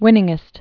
(wĭnĭng-ĭst)